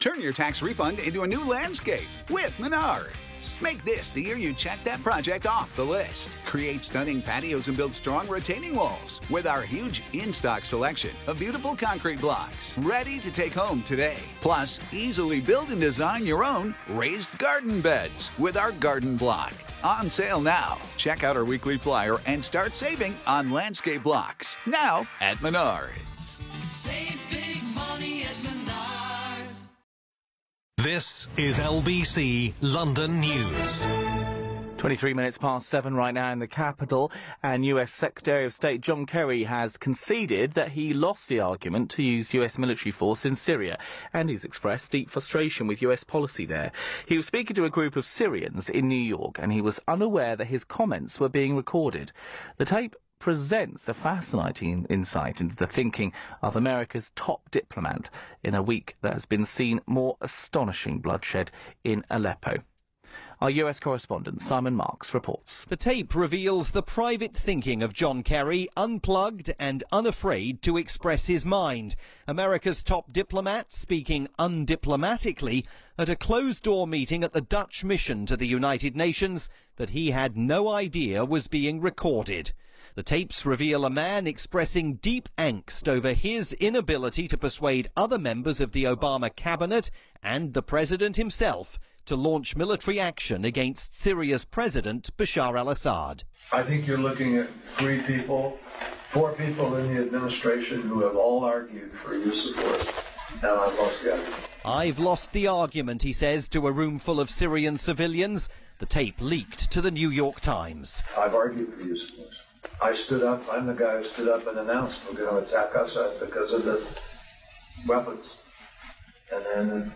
With the situation in Syria worsening by the day, John Kerry has privately conceded that he lost the argument inside the Obama administration about whether to strike militarily at President Bashar al Assad. In comments that were secretly recorded, Mr. Kerry expresses deep frustration with his own government, the Russians, and tells a group of Syrians there can now only be a political solution in their country.